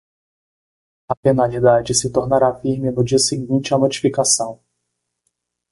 Pronúnciase como (IPA)
/ˈfiʁ.mi/